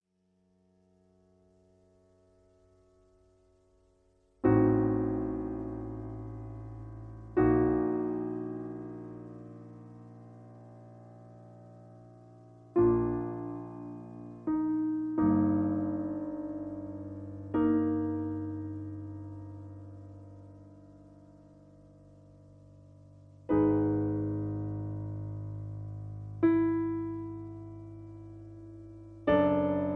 Half step lower. Piano Accompaniment